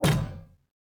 parry_1.ogg